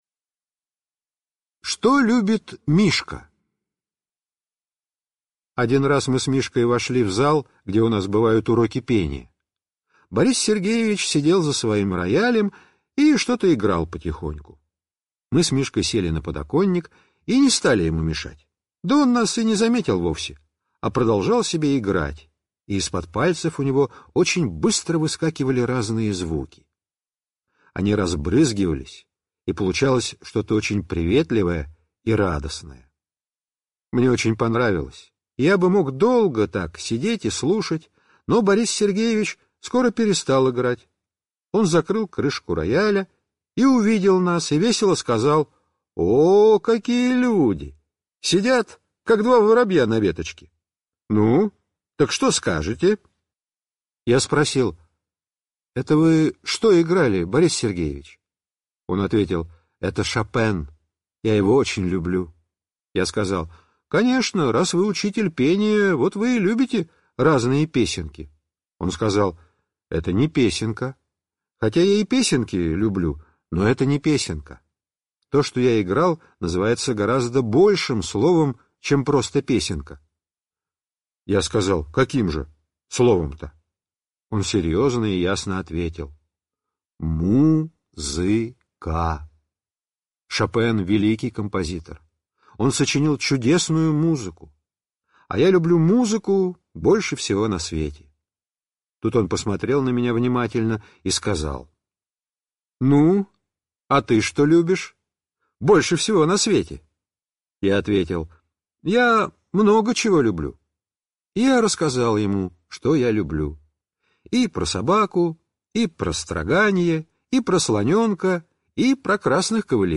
теги: Денискины рассказы, рассказы, Драгунский Виктор, аудиокнига